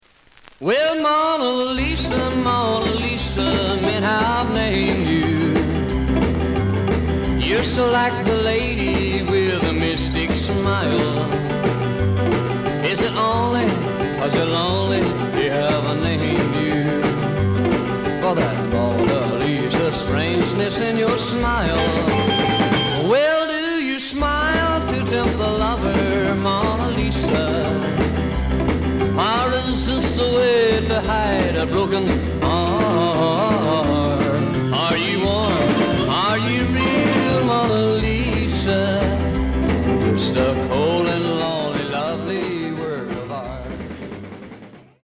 The listen sample is recorded from the actual item.
●Genre: Rhythm And Blues / Rock 'n' Roll